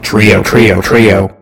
Audio / SE / Cries / DUGTRIO.mp3